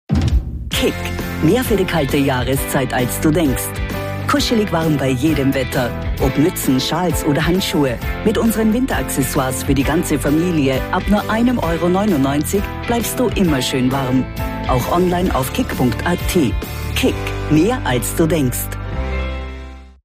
Alemán (Austria)
Seguro, Amable, Natural, Mayor, Cálida
Comercial